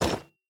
Equip_copper4.ogg.mp3